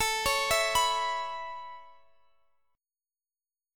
Listen to Aadd9 strummed